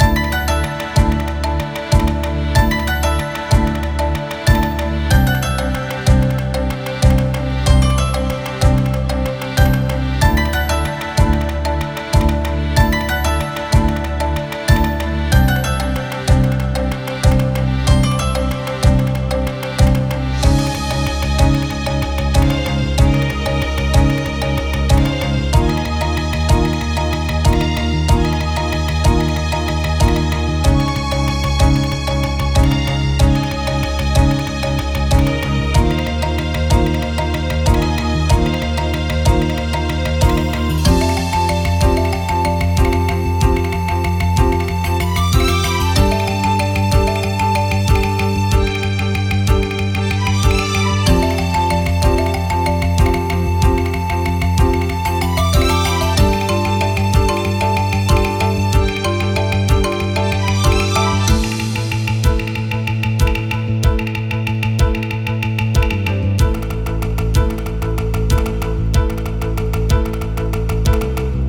Country - Grasslands.ogg